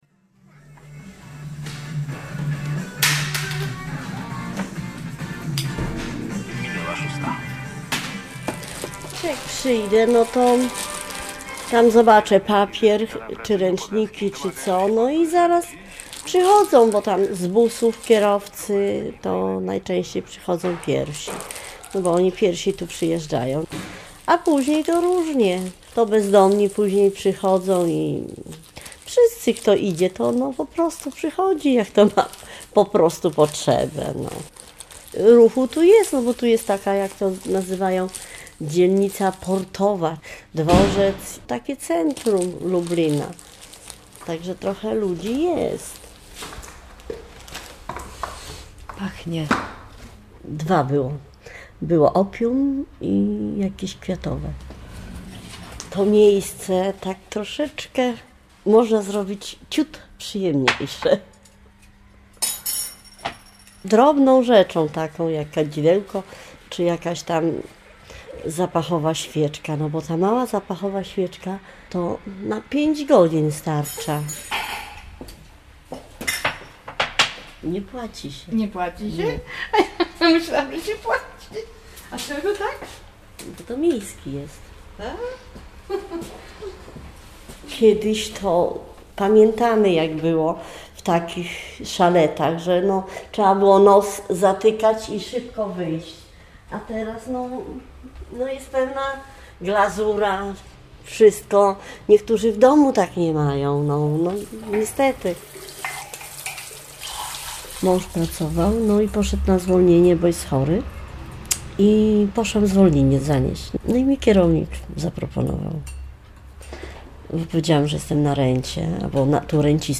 Tagi: reportaż